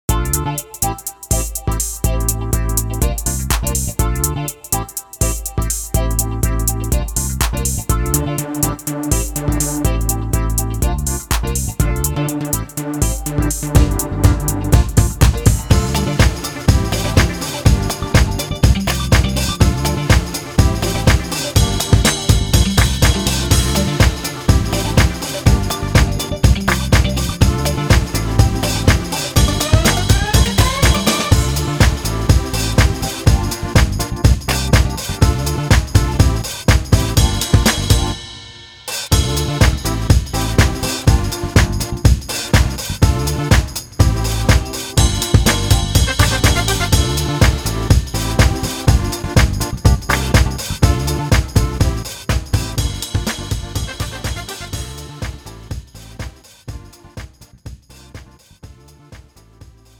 음정 (-2키)
장르 가요 구분 Lite MR